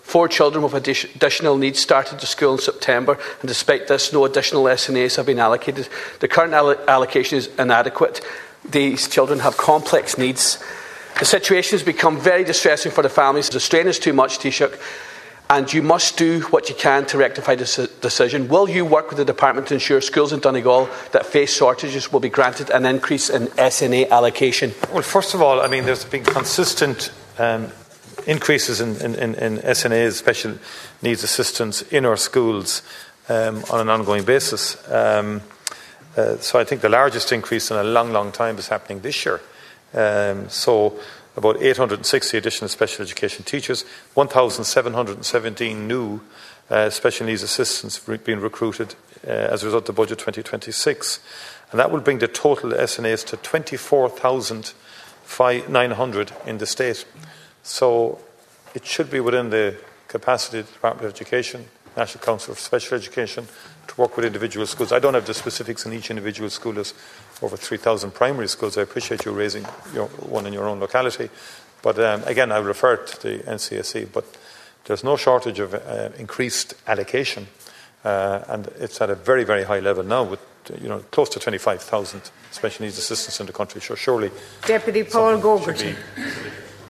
Deputy Charles Ward told the Dail this afternoon that there is a need for more supports at the school, but that hasn’t been forthcoming.